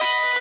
guitar_017.ogg